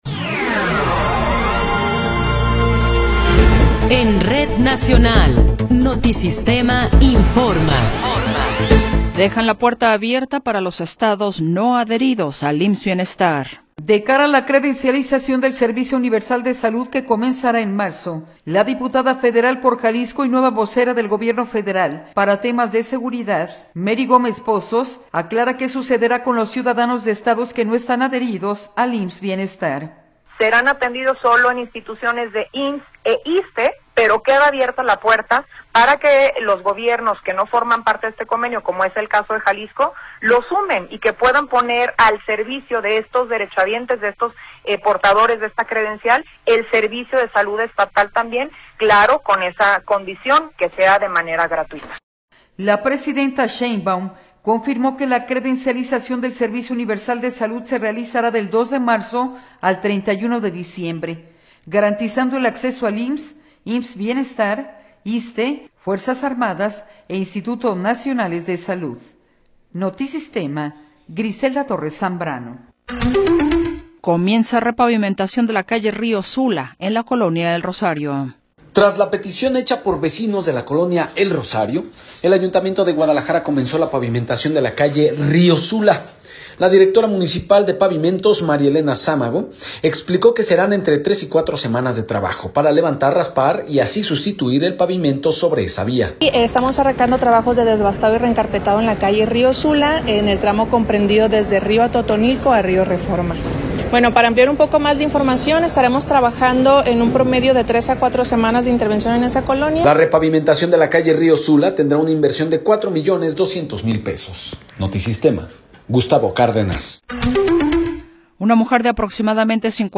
Noticiero 17 hrs. – 21 de Enero de 2026